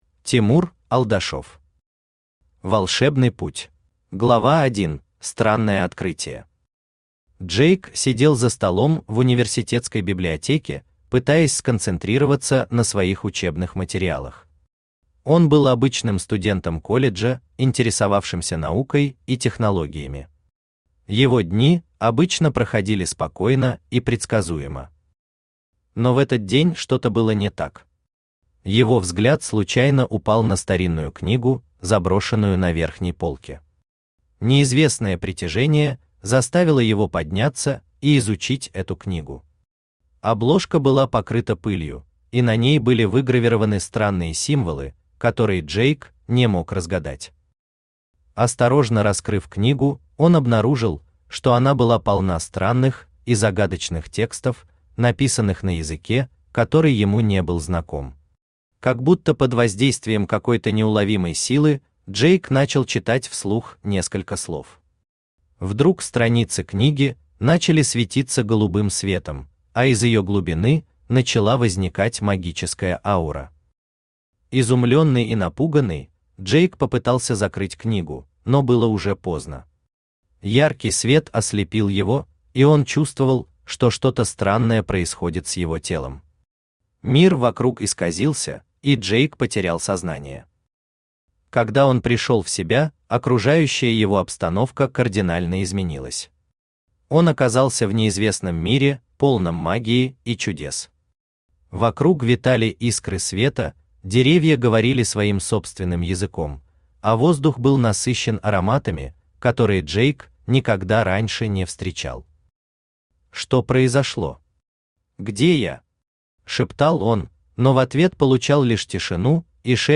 Аудиокнига Волшебный путь | Библиотека аудиокниг
Aудиокнига Волшебный путь Автор Тимур Алдашев Читает аудиокнигу Авточтец ЛитРес.